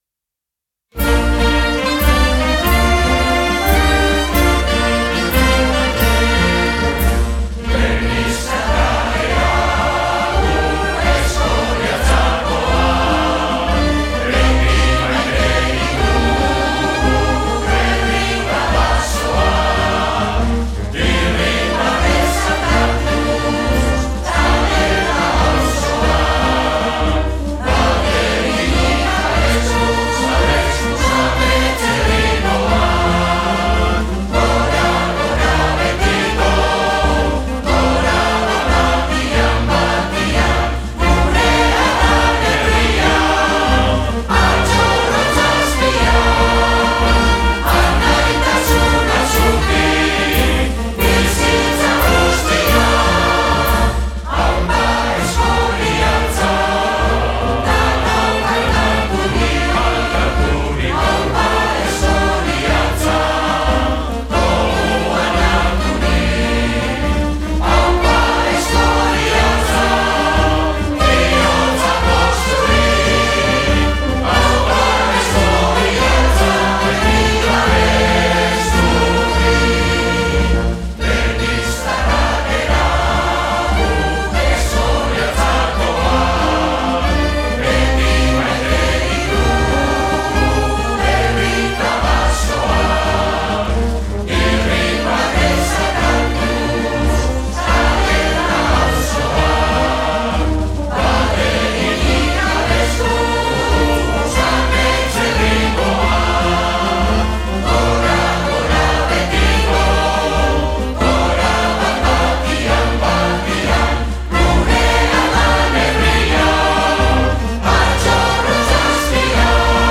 Grabación año 2025, banda y voces: